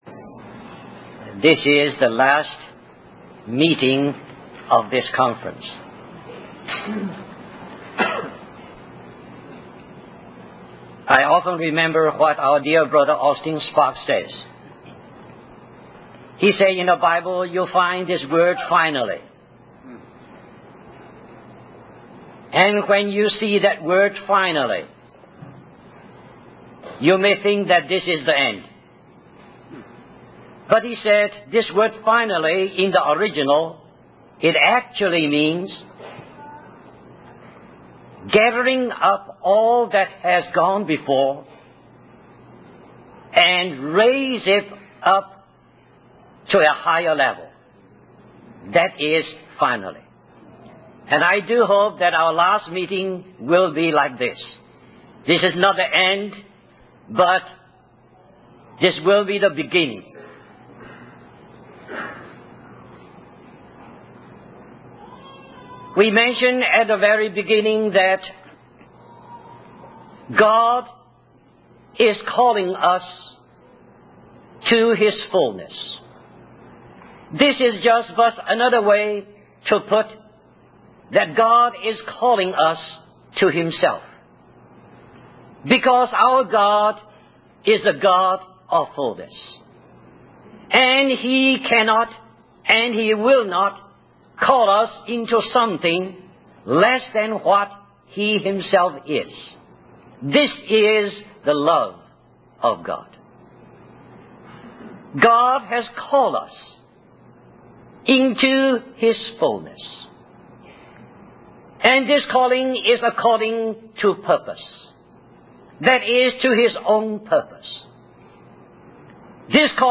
In this sermon, the speaker discusses the principle of bearing much fruit through dying. He then goes on to talk about four areas where this principle can be applied: personal life, corporate life, ministry, and the recovery of the testimony of Jesus.